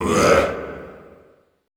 Index of /90_sSampleCDs/Best Service - Extended Classical Choir/Partition I/DEEP SHOUTS
DEEP UAE 2-L.wav